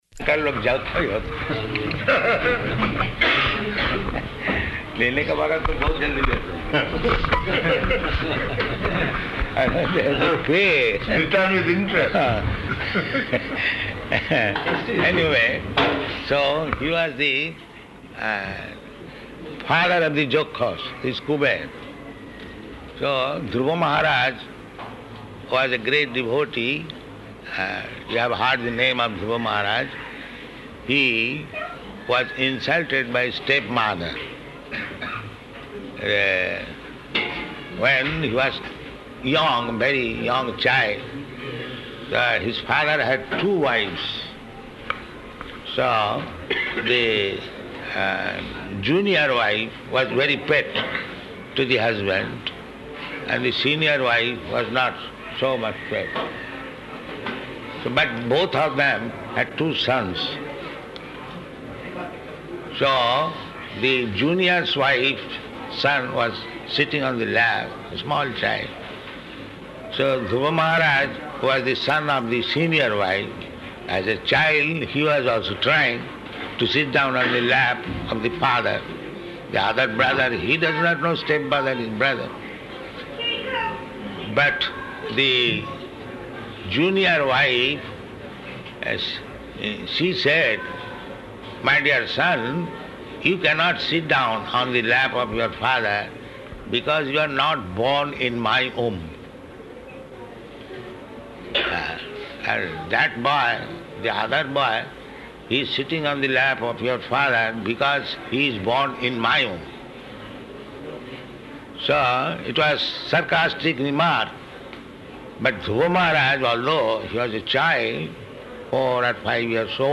Meeting with Bankers --:-- --:-- Type: Conversation Dated: April 6th 1976 Location: Vṛndāvana Audio file: 760406ME.VRN.mp3 Prabhupāda: [Hindi conversation with Indians] "You pay."